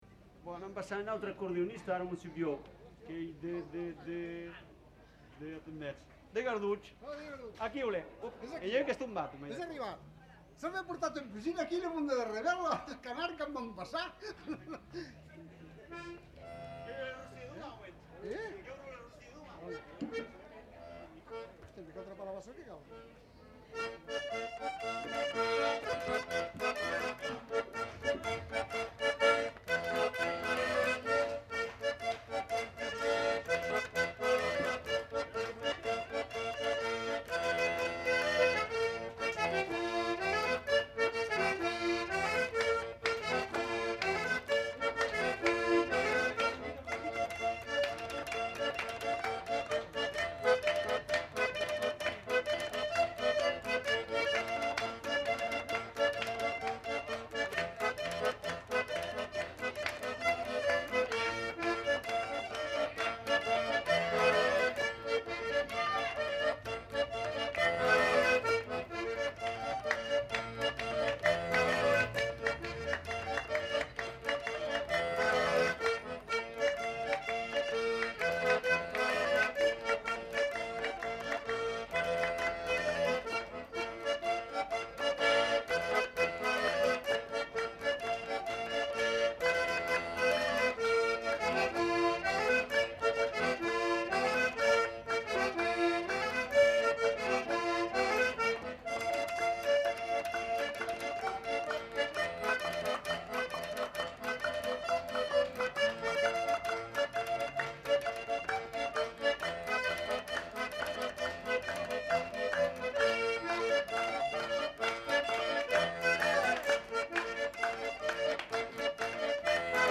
Aire culturelle : Lauragais
Lieu : Revel
Genre : morceau instrumental
Instrument de musique : accordéon